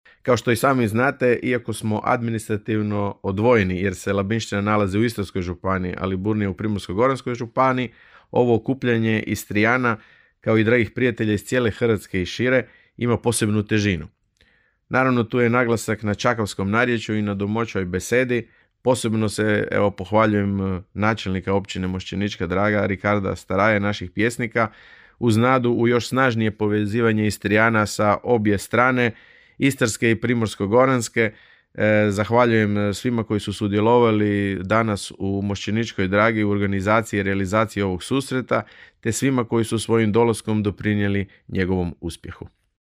Ne manje zadovoljan bio je i raški općinski načelnik Leo Knapić: (